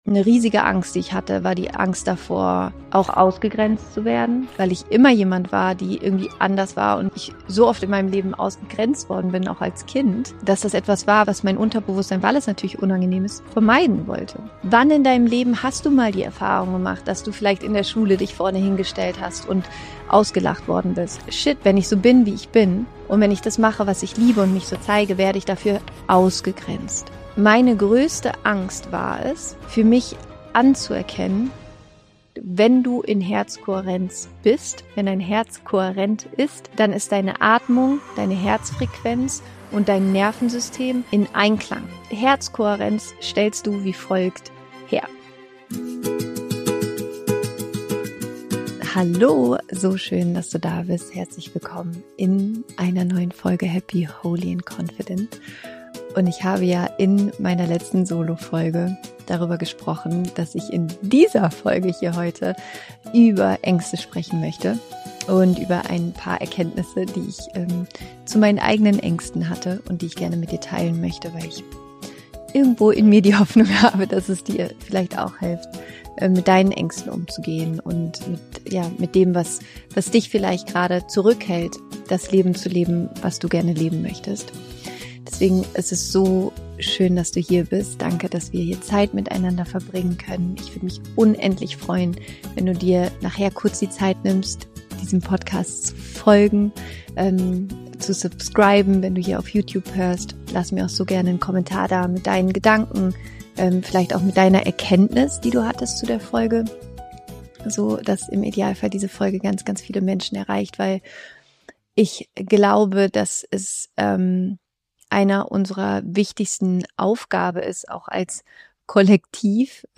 Am Ende der Folge wartet außerdem eine kleine Meditation auf dich, um in deine Herzkohärenz zu kommen – das kraftvollste Tool, das ich kenne, um dich aus der Angst zurück in die Verbindung zu bringen.